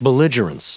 Prononciation du mot belligerence en anglais (fichier audio)
Prononciation du mot : belligerence